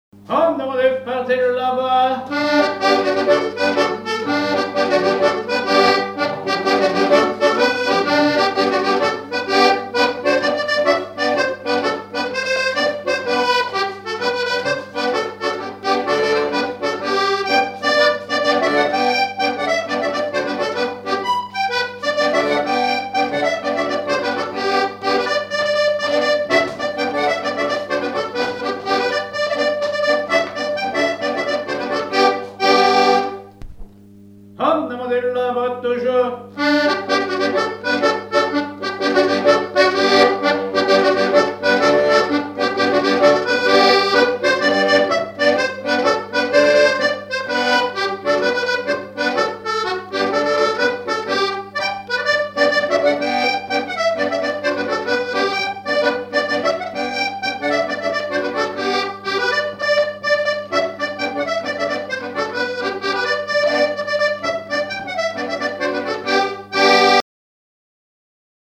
danse : branle : avant-deux
Pièce musicale inédite